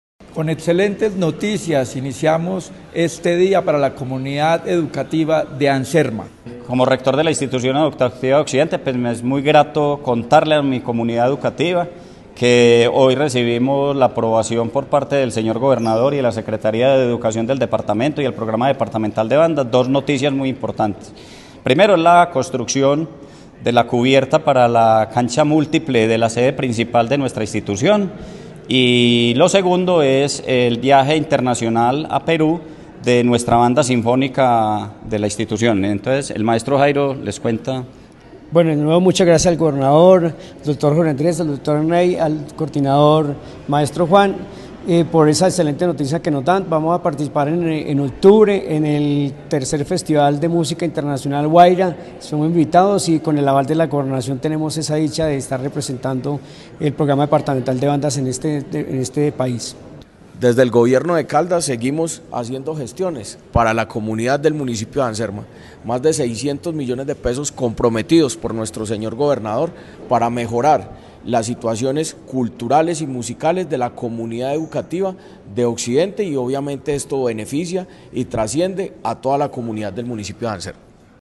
Declaraciones-Institucion-Educativa-Anserma.mp3